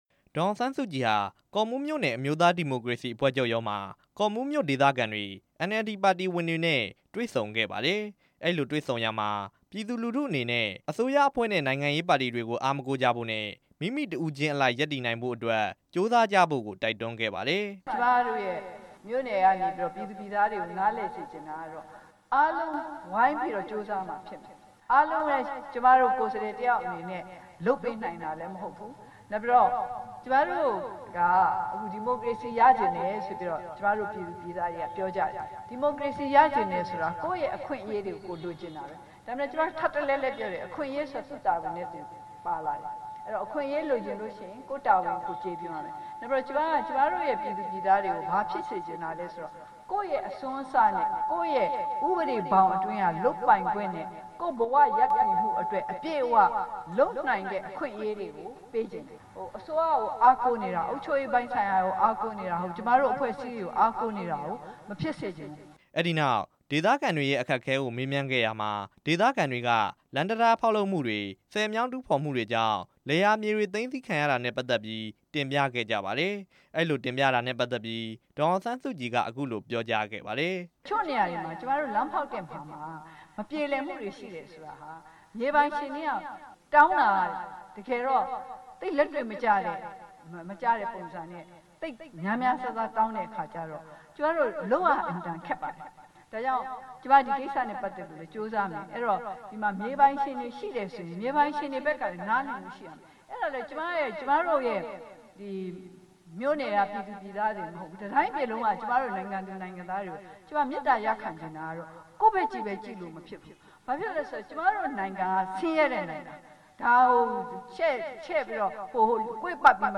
ဒေါ်အောင်ဆန်းစုကြည် ပြောကြားချက်များ
ကော့မှူးမြို့နယ် အမျိုးသားဒီမိုကရေစီအဖွဲ့ချုပ်ရုံး မှာ ဒေသခံတွေနဲ့ ဒီကနေ့ တွေ့ဆုံစဉ်မှာ ဒေါ်အောင် ဆန်းစုကြည်က အခုလိုပြောကြားခဲ့တာပါ။